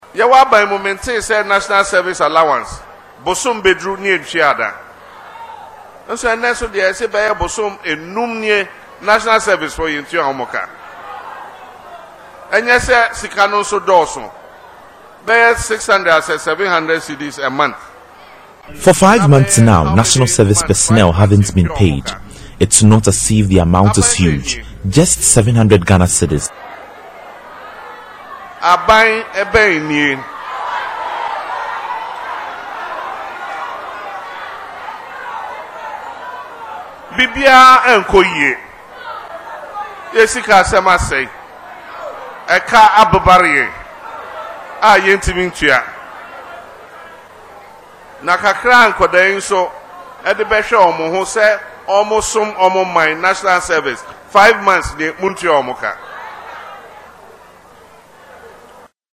Speaking in local parlance during his tour on March 22, he recalled that during his tenure in office as President, service personnel allowances were never delayed, and he is, therefore, surprised that this is now the case.